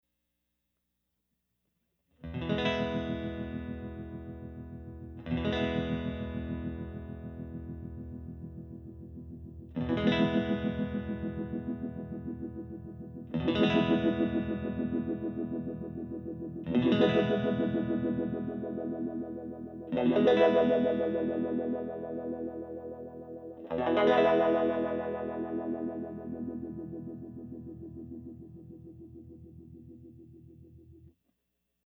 The sweep modes are ÒchoppyÓ and Òsmooth,Ó which refer to how quickly the sweep travels along the frequency range.
Sweeping the LF Setpoint control:  Clock Trigger, Choppy Mode